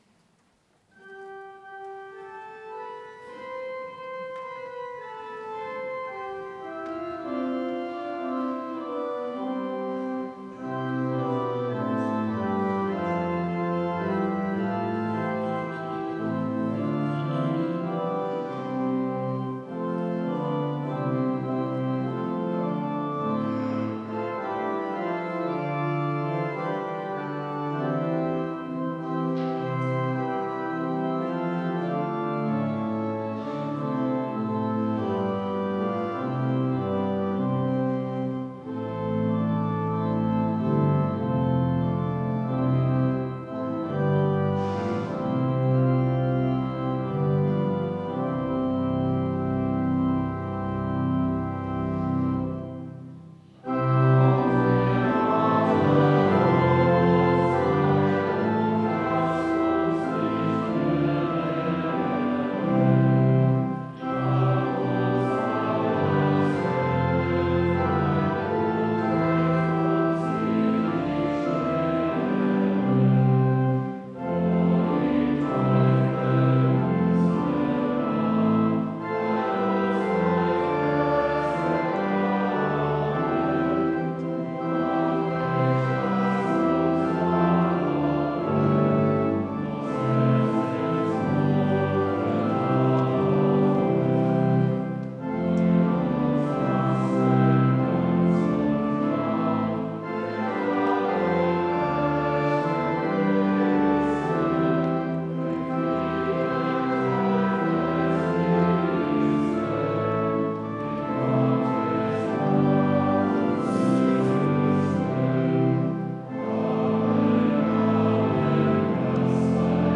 Audiomitschnitt unseres Gottesdienstes am Sonntag Invokavit 2025.